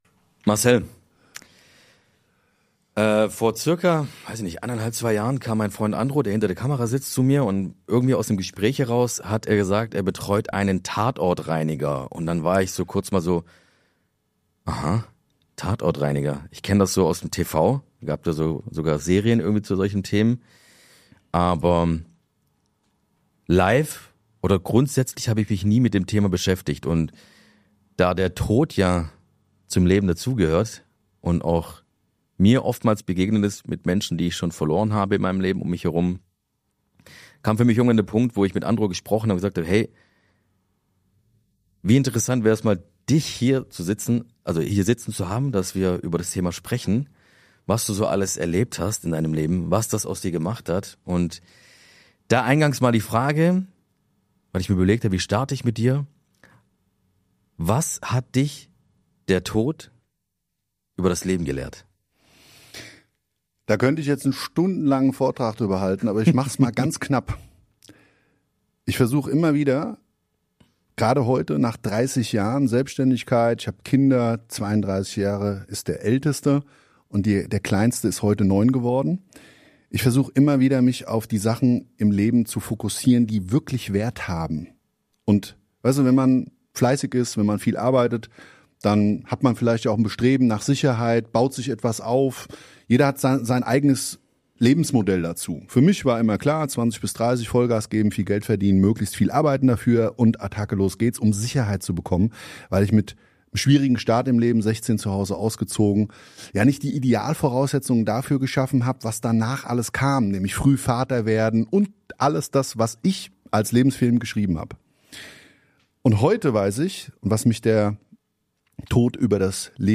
Ein Gespräch über Tatortreinigung, Tod, Verantwortung und den Preis, den Wegsehen hat.